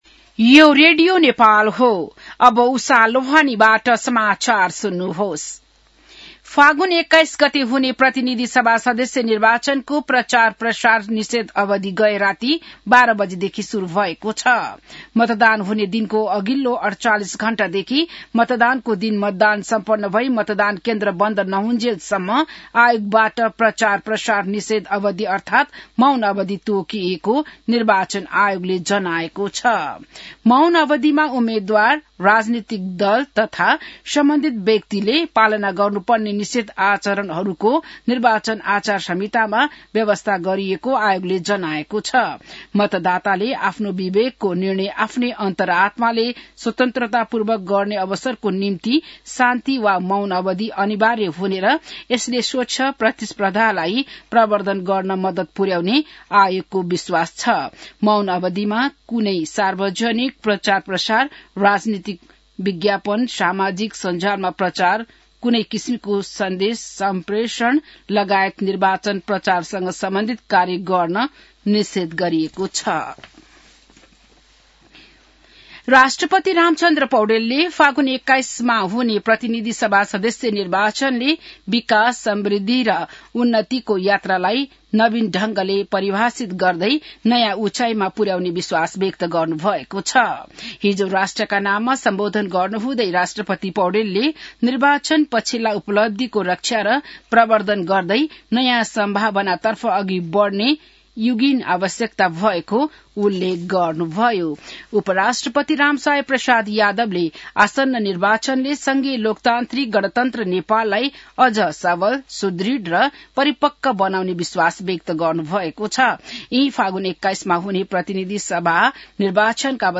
An online outlet of Nepal's national radio broadcaster
बिहान १० बजेको नेपाली समाचार : १९ फागुन , २०८२